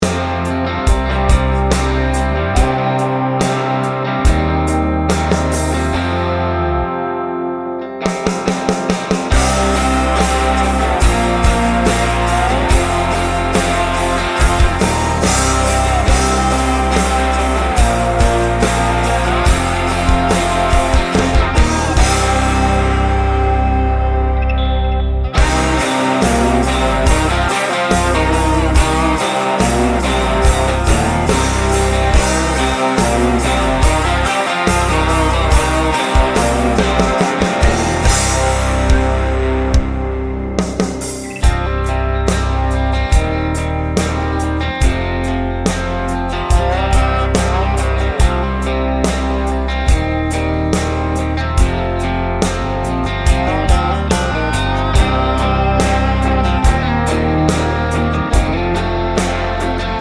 no Backing Vocals Country (Male) 3:28 Buy £1.50